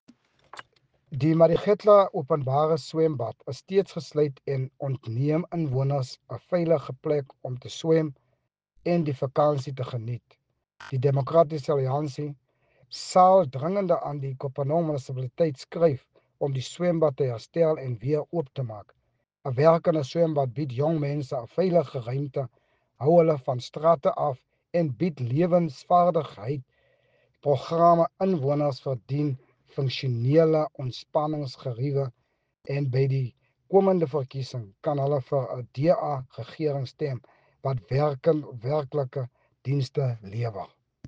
Afrikaans soundbites by Cllr Richard van Wyk and